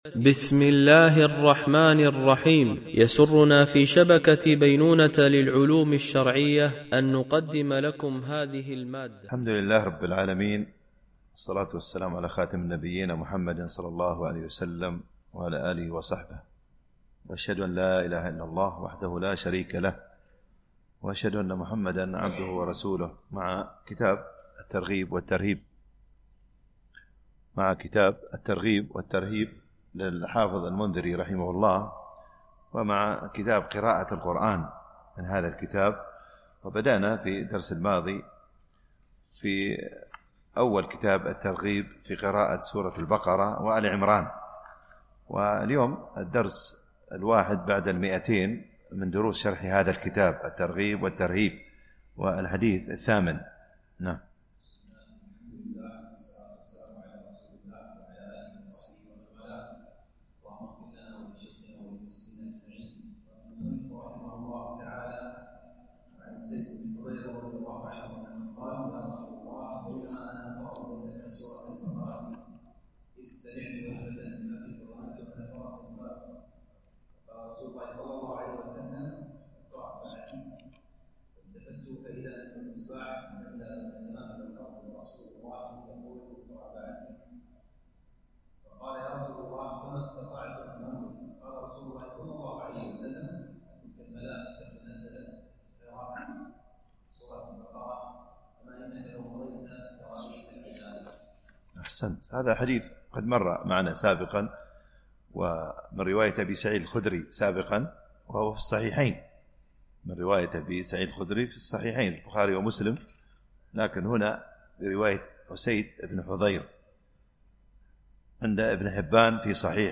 MP3 Mono 44kHz 64Kbps (VBR)